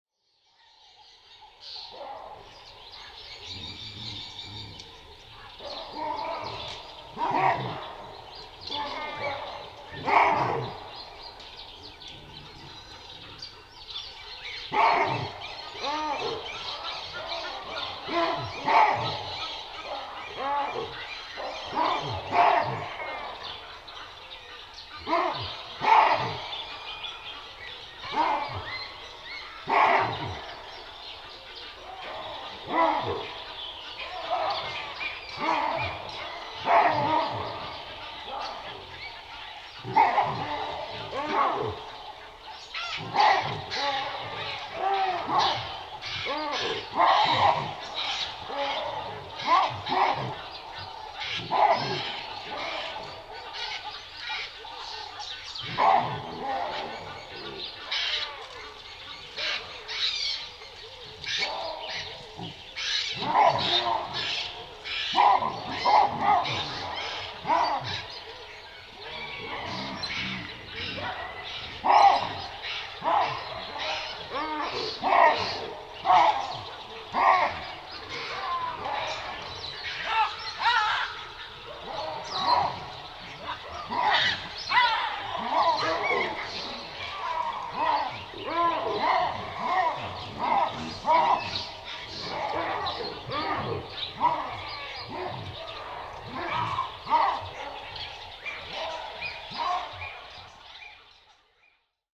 Chacma Baboon (Papio ursinus)
Chacma Baboon – Group alarm calls
M6EW-1008 This audio sample features a cacophony of wild alarm barks from a large troop of Chacma Baboons ealr one morning along the banks of the Limpopo River, Botswana. It is likely the baboons had spotted one of the leopards or lions which were present in the area.